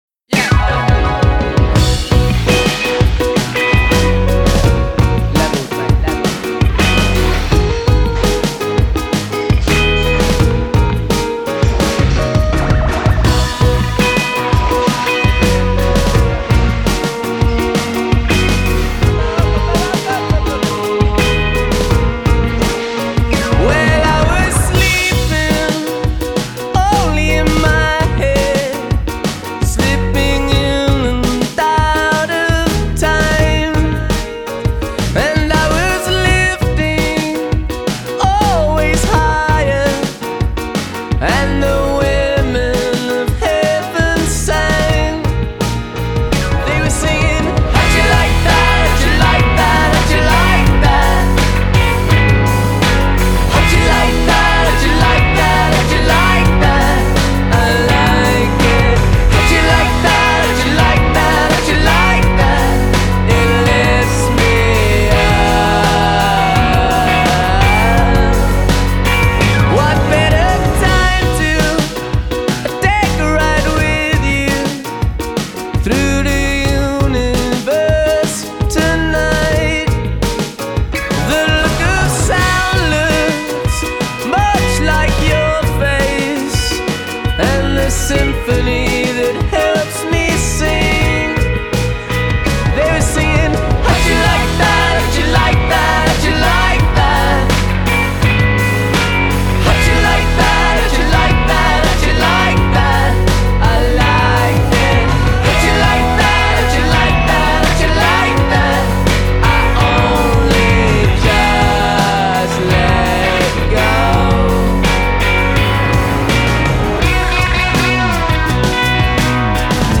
Style: Indie rock